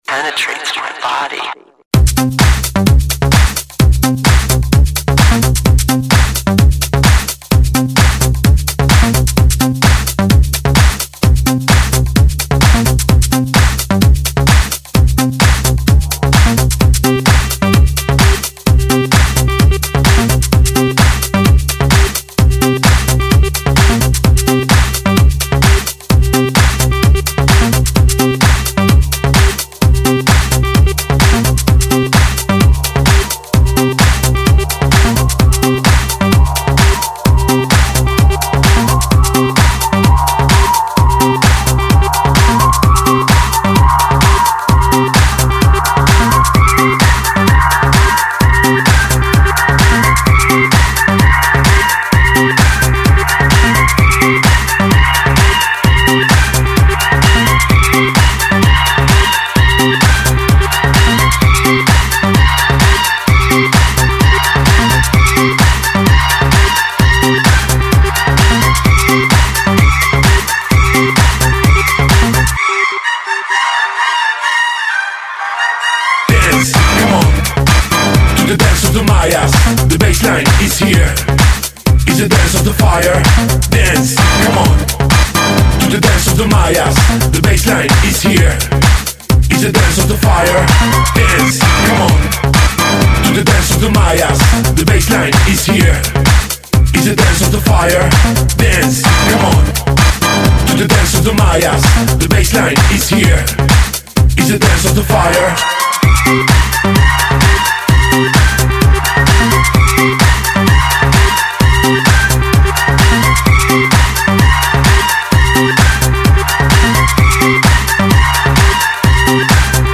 Назад в ¤Super / Club / Dance¤